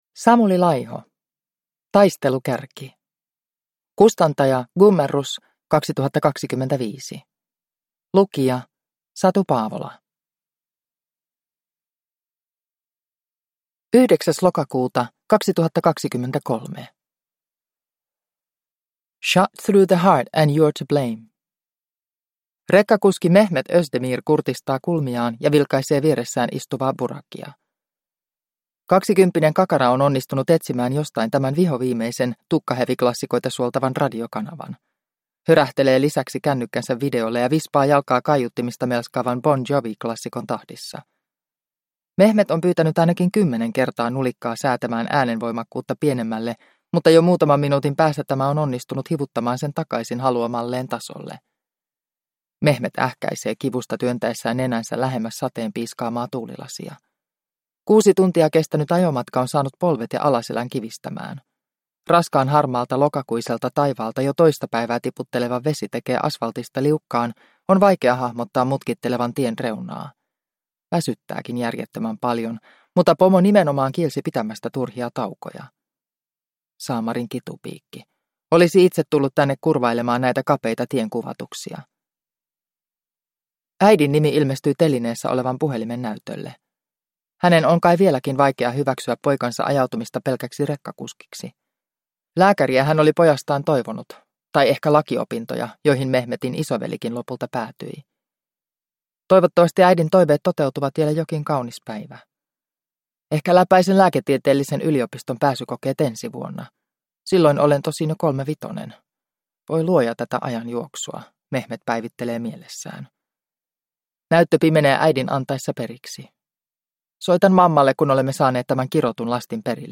Taistelukärki (ljudbok) av Samuli Laiho